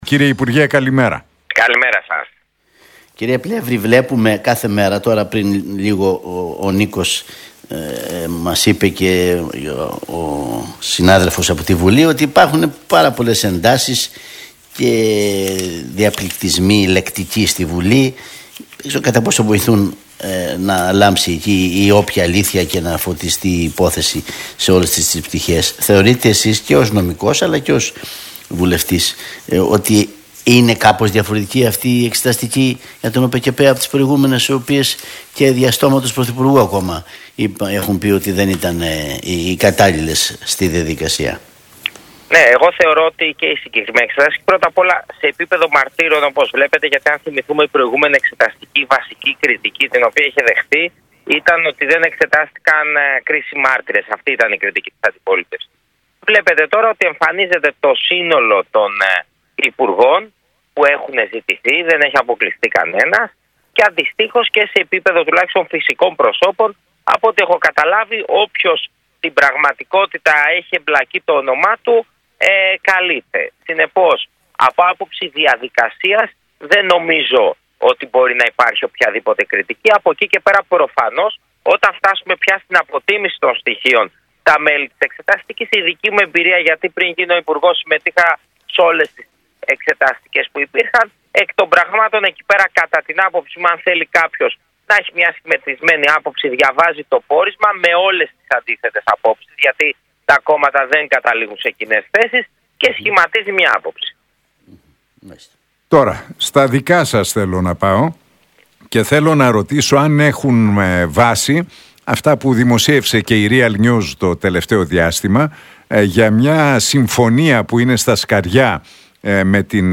Πλεύρης στον Realfm 97,8: Η Ελλάδα συμμετέχει στις συζητήσεις για δημιουργία κέντρου επαναπατρισμού παράνομων μεταναστών στην Αφρική — ΔΕΔΟΜΕΝΟ